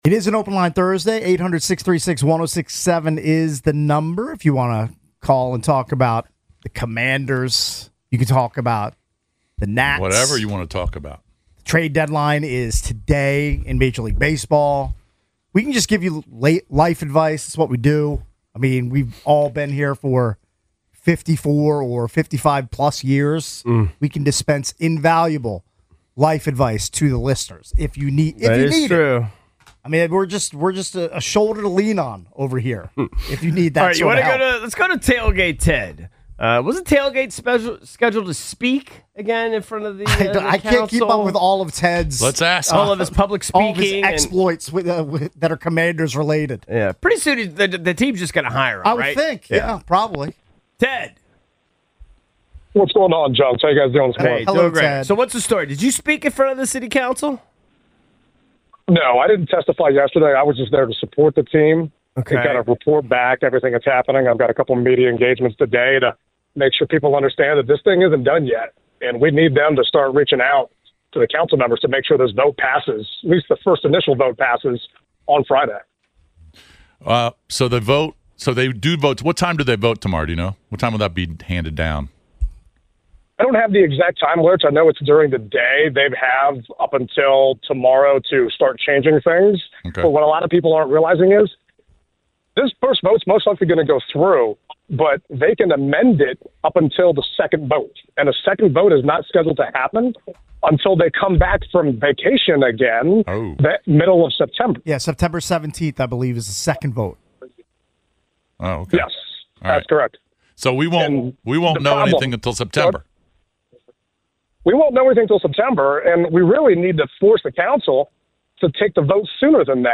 The Sports Junkies react to High Noon's big mistake.